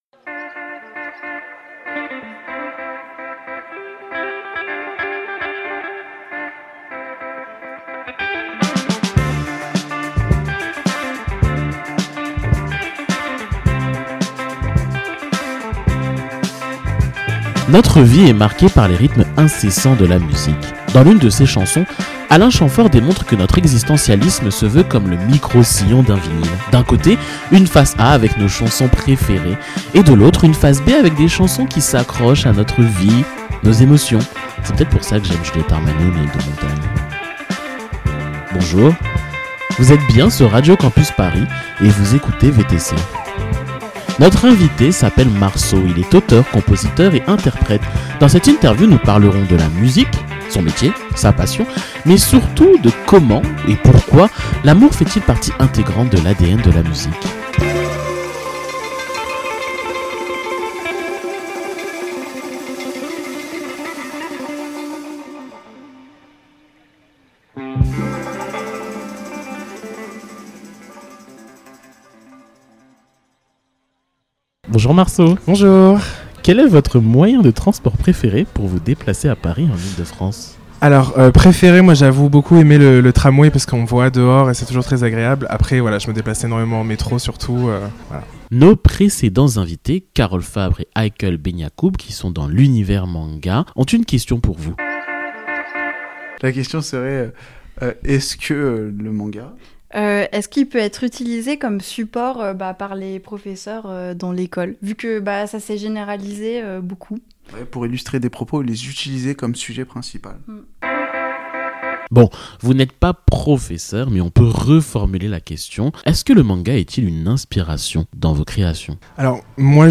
Partager Type Entretien Société Culture mardi 27 février 2024 Lire Pause Télécharger Mes chansons sont comme une thérapie […]